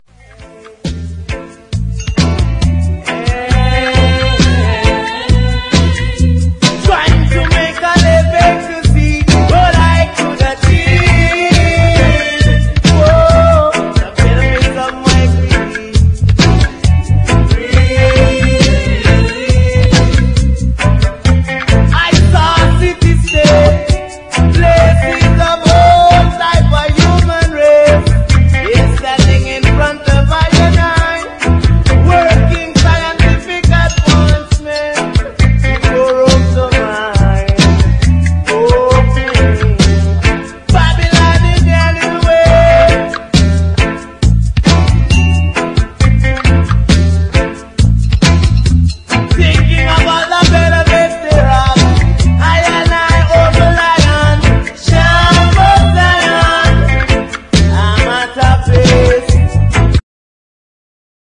REGGAE / SKA/ROCKSTEADY / NEO SKA
ドイツはヴィースバーデンを拠点とする10人組ネオスカ・バンド！